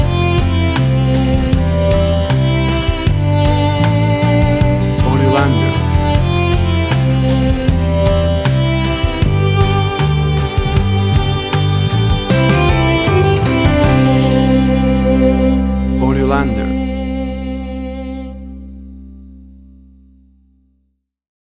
WAV Sample Rate: 16-Bit stereo, 44.1 kHz
Tempo (BPM): 155